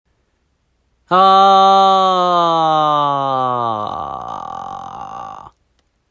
２、続いて、地声でアーっと声を出し。徐々に音程を低くしていきます。
一番低い声になると　自然とア¨ーという音のような声になると思います。
アーーーア¨ア¨ア¨
これをエッジボイスといいます。